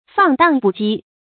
fàng dàng bù jī
放荡不羁发音
成语正音 羁，不能读作“ē”。